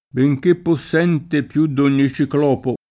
bejk% ppoSS$nte pL2 dd on’n’i ©ikl0po] (Pindemonte) — per lo più con C- maiusc. se inteso come nome proprio